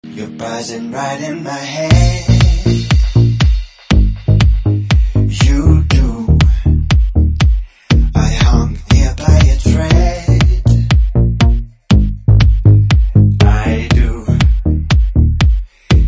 заводные
dance
club
house